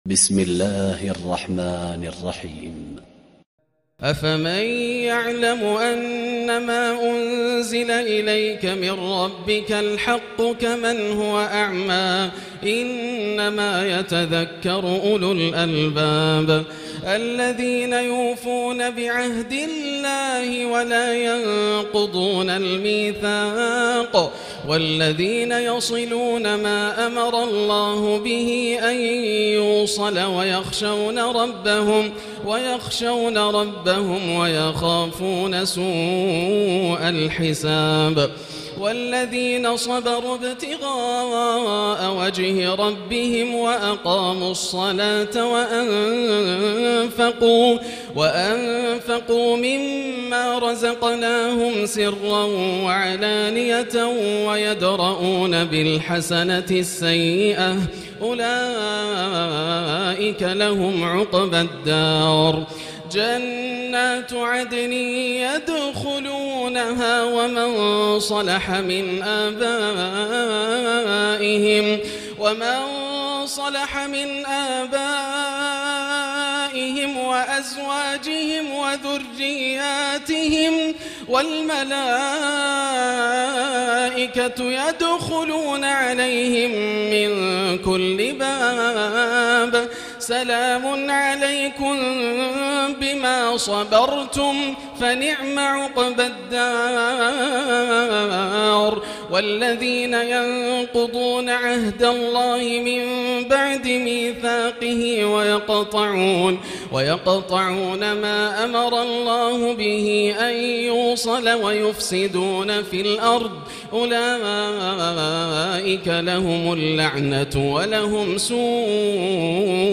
الليلة الثانية عشر تلاوة من سورة الرعد19-43 وسورة إبراهيم كاملة > الليالي الكاملة > رمضان 1439هـ > التراويح - تلاوات ياسر الدوسري